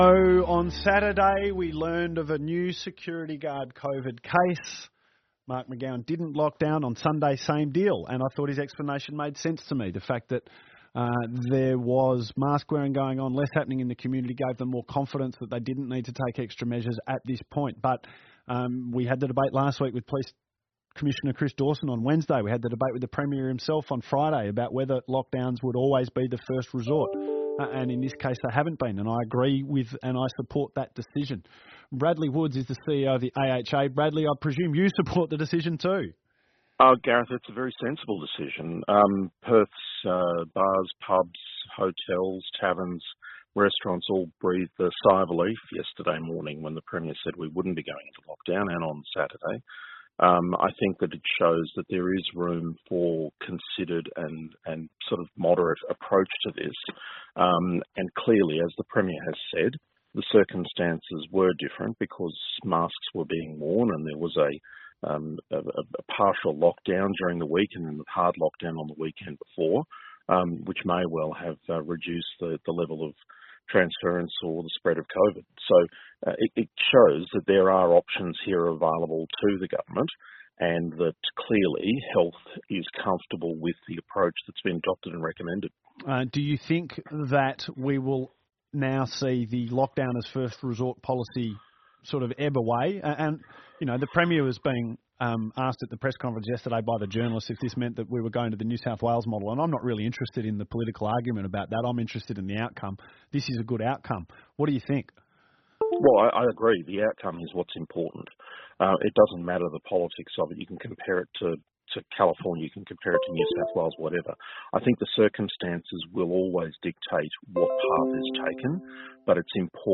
interview with 6PR – Hospitality industry ‘breathe a sigh of relief’ after state avoids lockdown